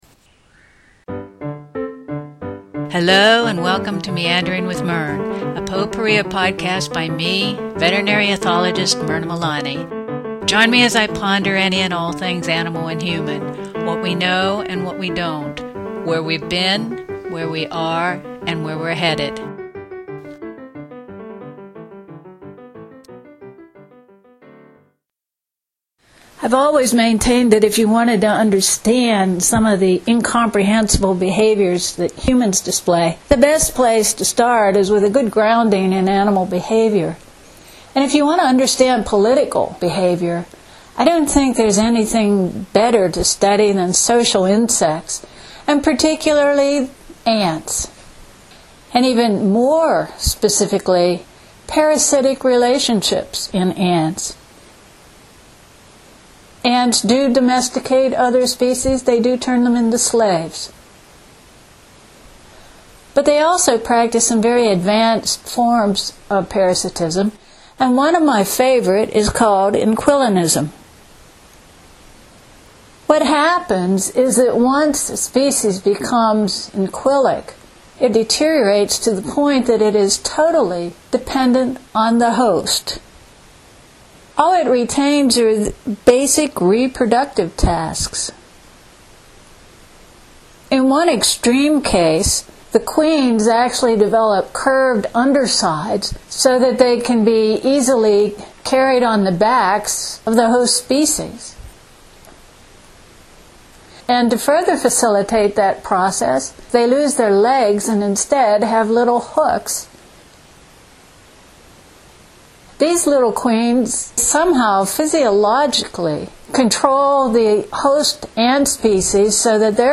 For those who wondered about the identity of the strange aerial sound  that set him off, it was a very small aircraft flying low in the early morning fog.